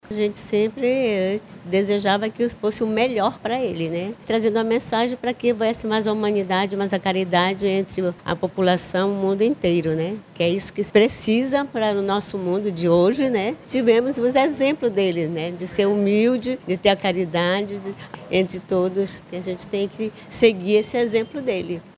Na Igreja de São Sebastião, localizada no Centro de Manaus, fiéis participaram de uma missa em sufrágio em intenção pela alma da santidade.